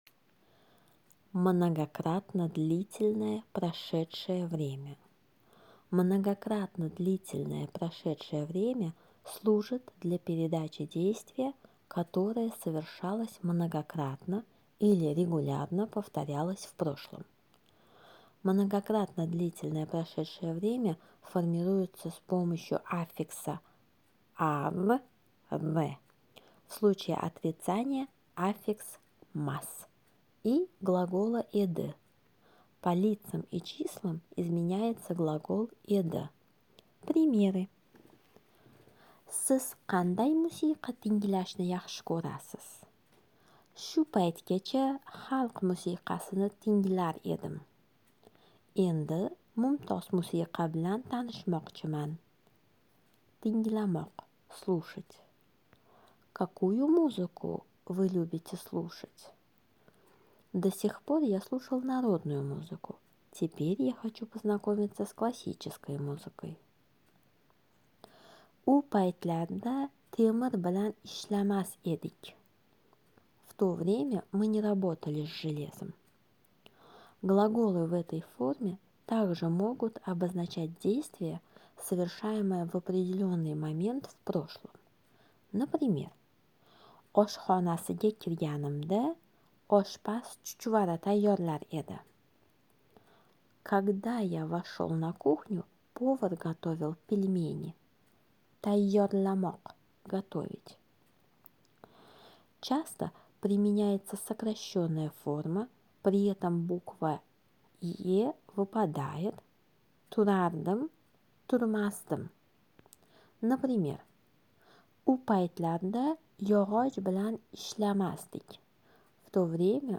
Узбекский язык - аудиоуроки